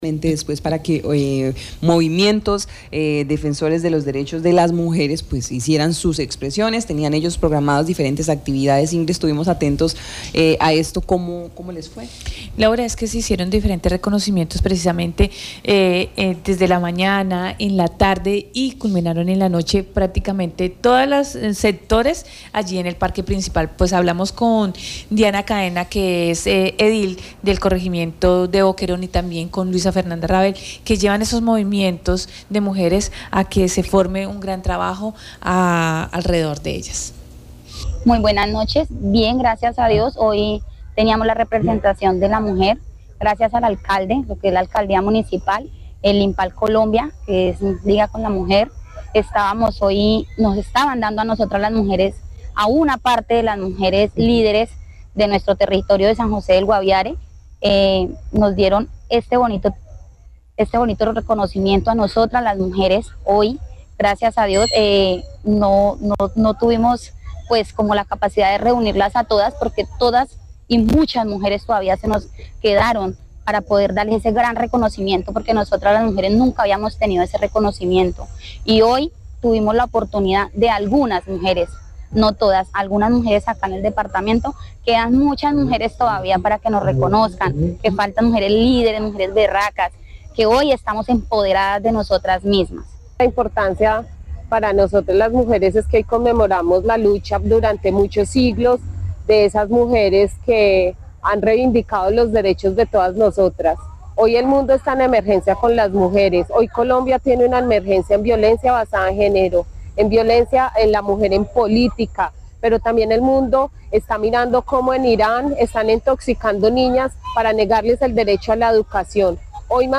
son algunas de las mujeres que nos dieron su entrevista y comentaron sobre cada actividad que realizaron.